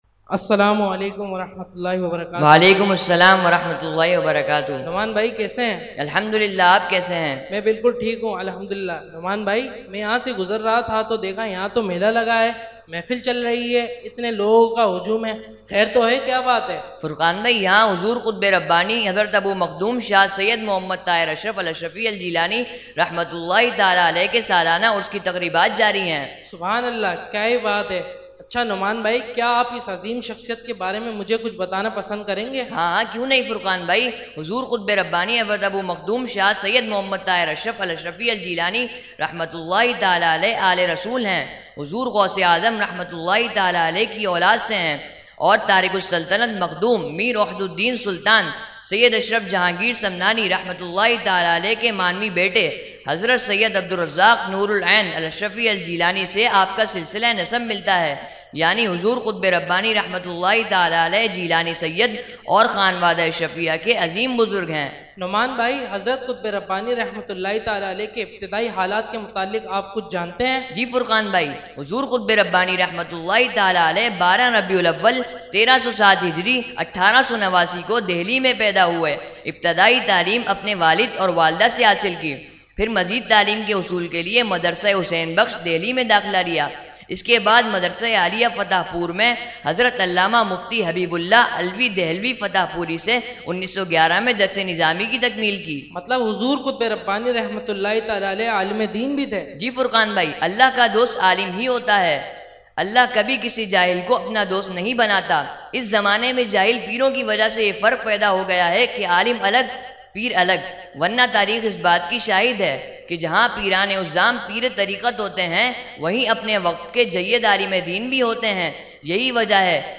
held on 1,2,3 January 2021 at Dargah Alia Ashrafia Ashrafabad Firdous Colony Gulbahar Karachi.